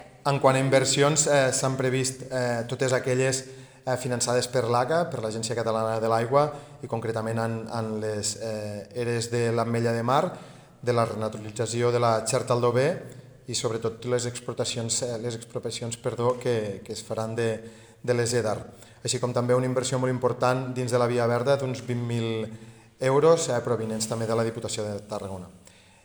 En l’apartat d’inversions, el conseller de Governació, Samuel Ferré, ha manifestat que es preveuen projectes en infraestructures com la millora de depuradores (ERA de l’Ametlla de Mar i EDAR de Xerta/Aldover), així com l’ampliació de la Via Verda del Carrilet de la Cava, que es preveu inaugurar durant el 2025